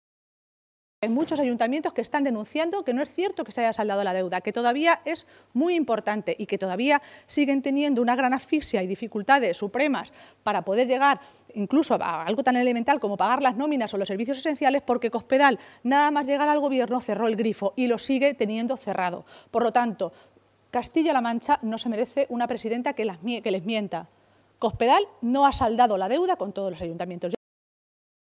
Maestre se pronunciaba de esta manera esta tarde, en una comparecencia ante los medios de comunicación minutos antes de que se reuniera en Toledo la dirección regional socialista.